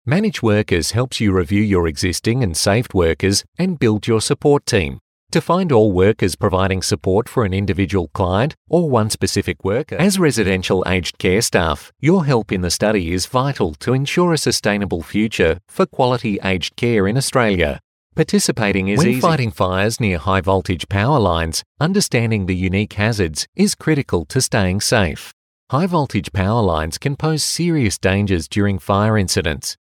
30s - 40s
Male
Australian
Natural
Hard Sell
Narration